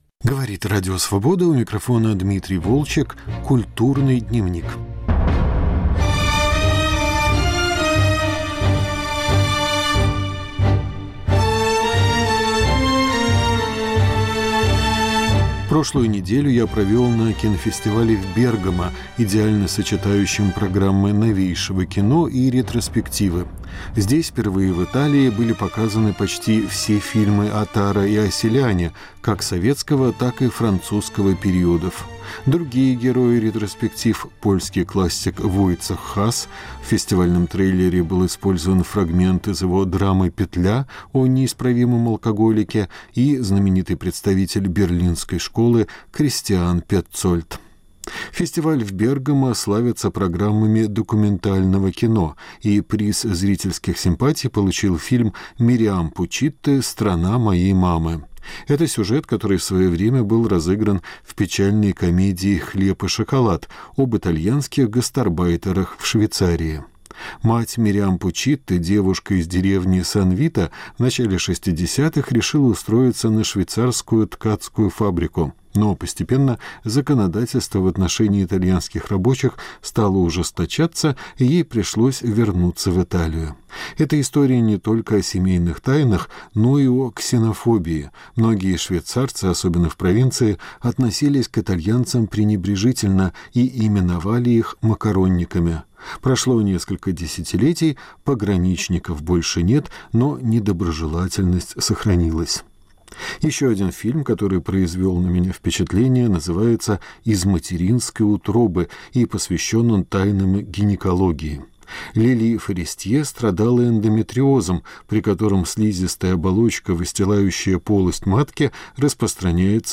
Разговоры на фестивалях в Бергамо и Праге